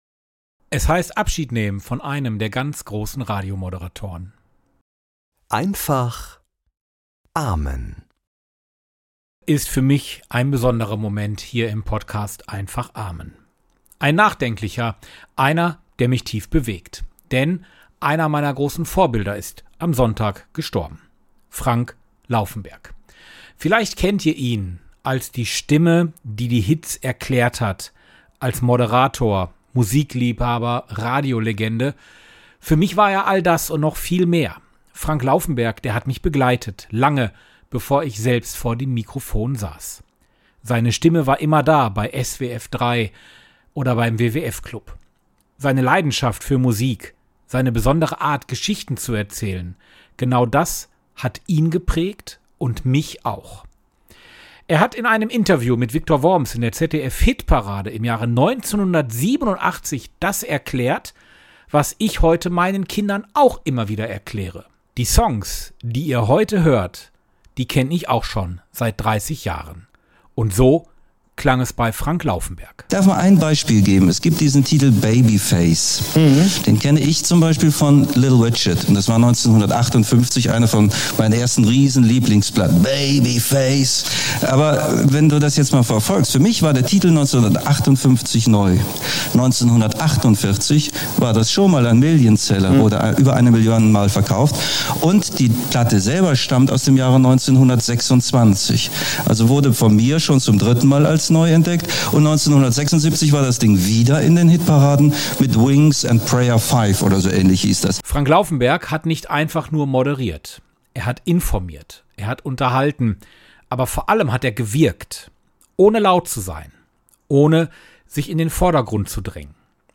Morgenimpuls in Einfacher Sprache